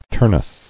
[Tur·nus]